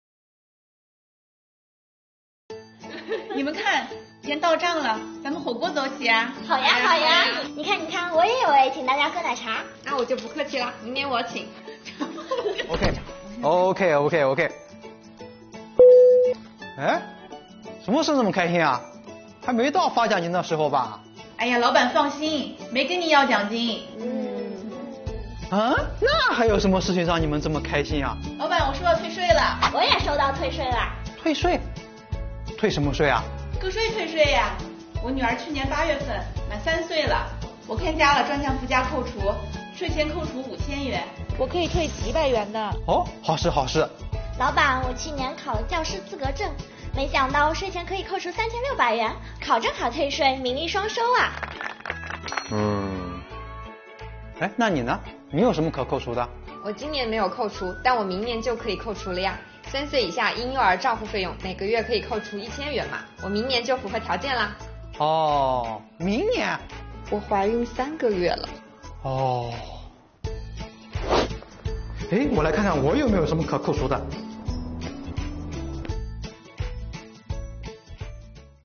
办公室里传来阵阵欢声笑语，大家在争先恐后地约火锅，约奶茶，难道是发奖金啦？
作品以情景剧的形式介绍了个税专项附加扣除的几种情形。